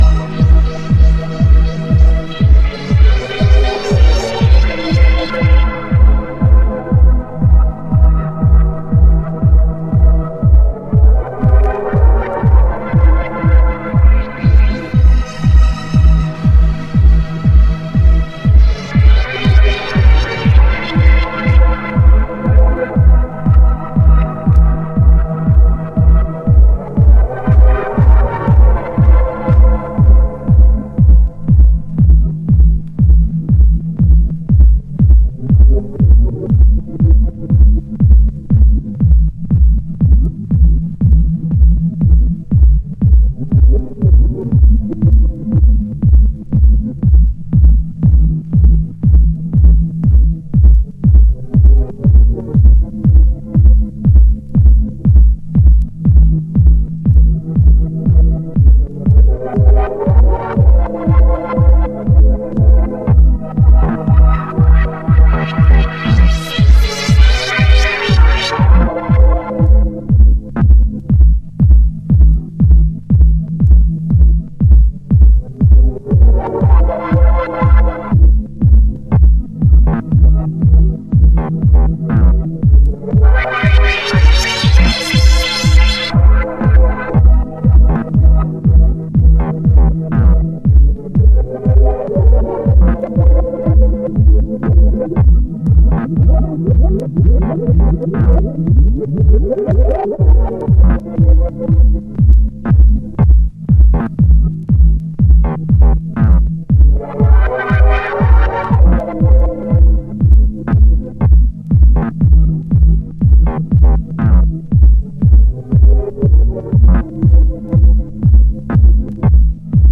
Detroit House / Techno
モコモコシンセが開閉を繰り返すミュータントハウスのオリジナル。